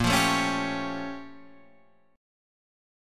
A# Minor Major 13th